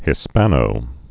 (hĭ-spănō, -spänō)